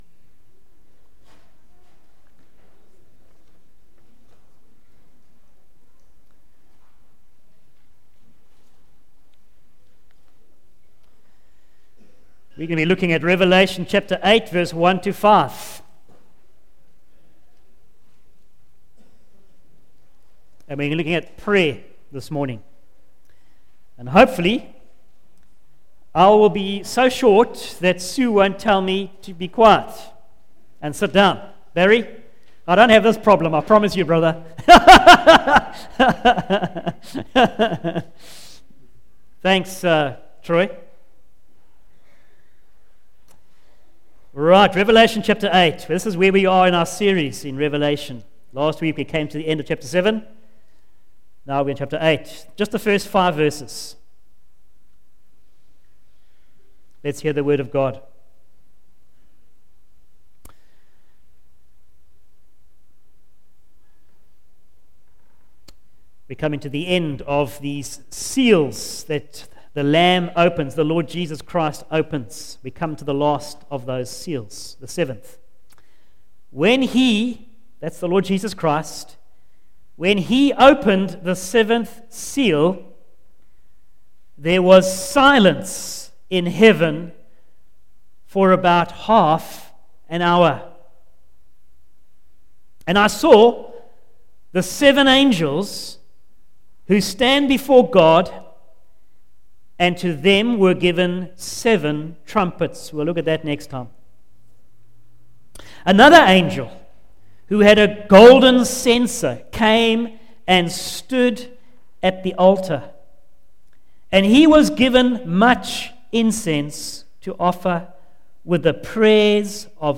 Facebook Twitter email Posted in Morning Service